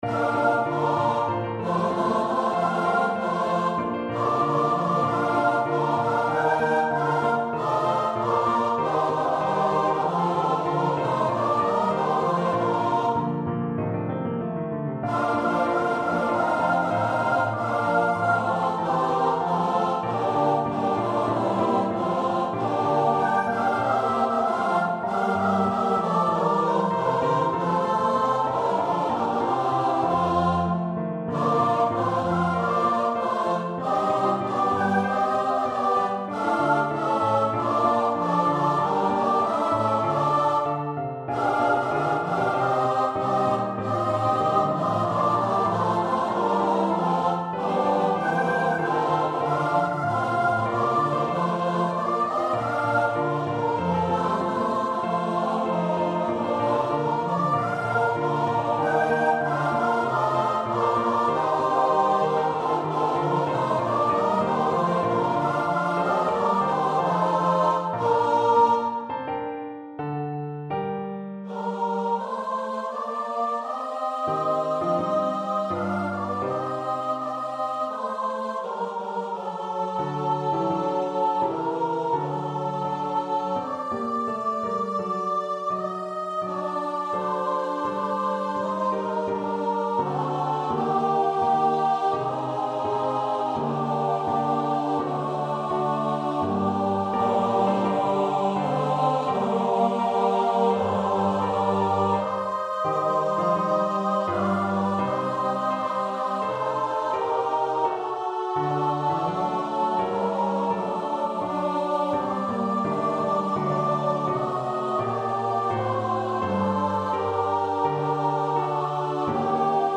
Free Sheet music for Choir (SATB)
Classical (View more Classical Choir Music)